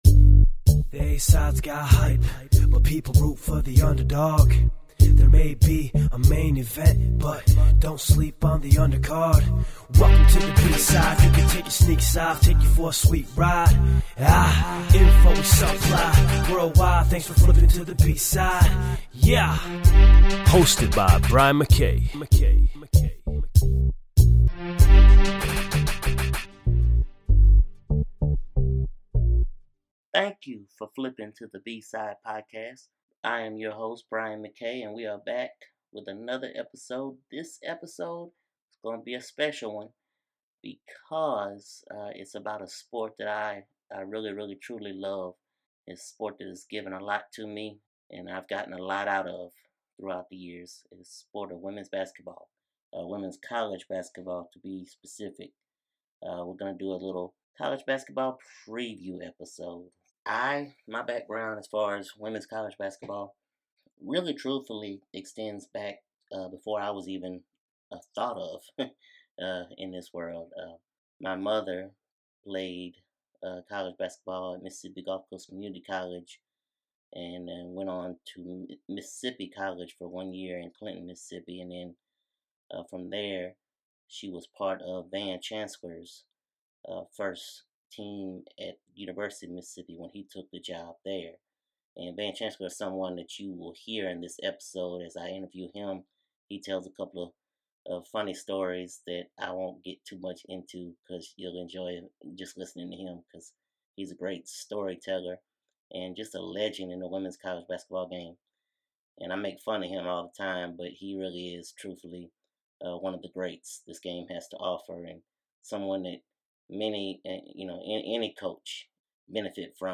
This episode is a mini 2018-2019 Women’s College Basketball Preview. We have interviews with Women’s Basketball TV/Radio Broadcaster, Debbie Antonelli (12:12-23:12) and Hall of Fame Coach, Van Chancellor (25:38-37:25).